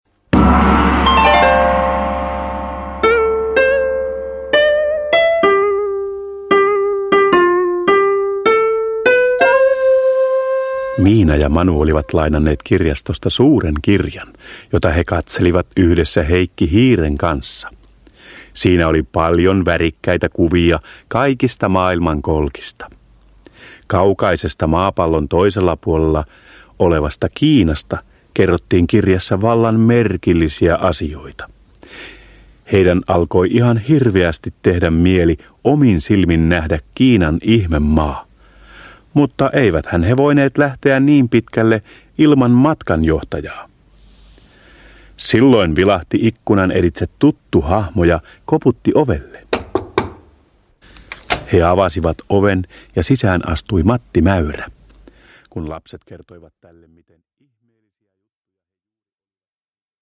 Miina ja Manu Kiinassa – Ljudbok – Laddas ner